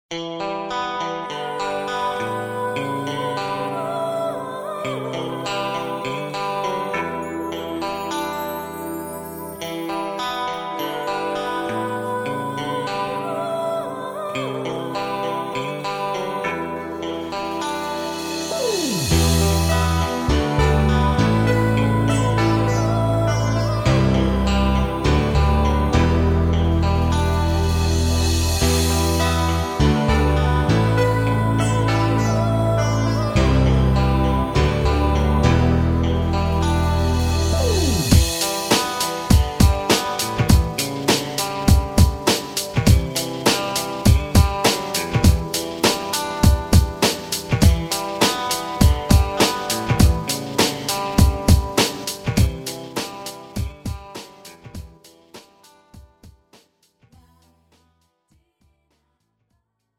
축가 및 결혼식에 최적화된 고품질 MR을 제공합니다!